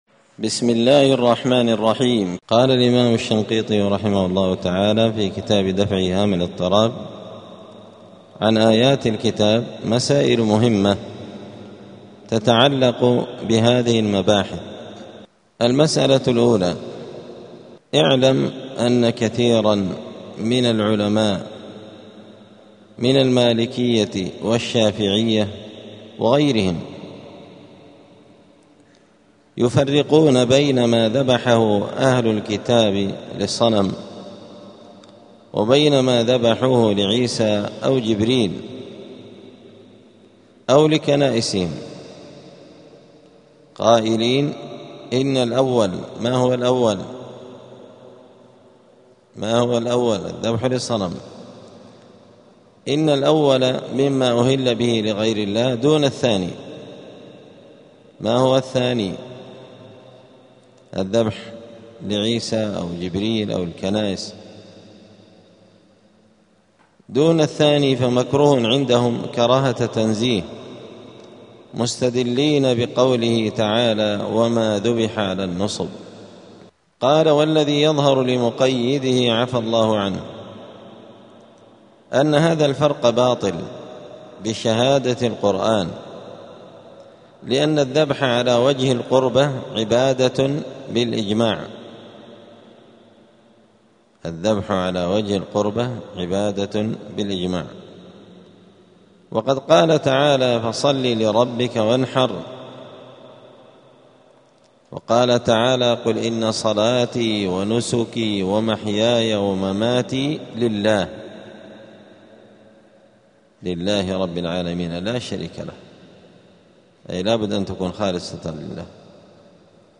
*الدرس الواحد الثلاثون (31) {سورة المائدة}.*